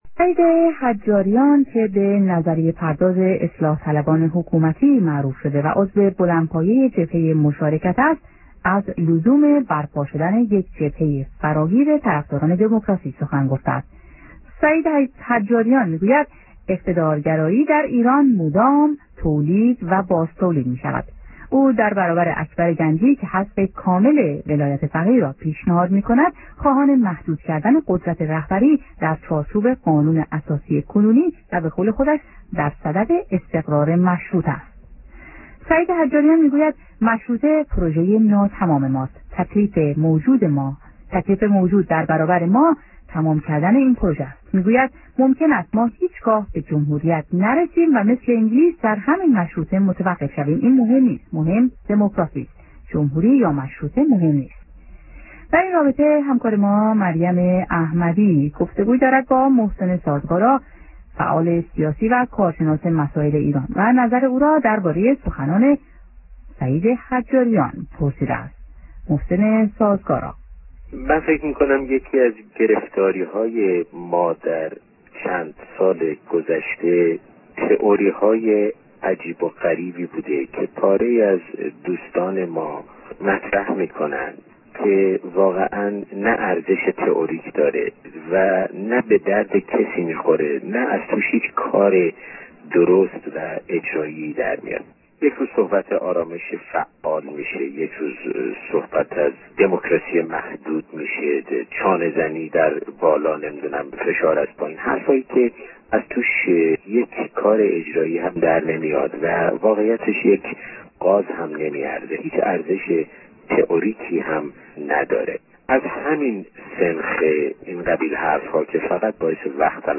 جمعه ۷ مردادماه ۱۳۸۴ - ۴:۳۲ بعدازظهر | مصاحبه ها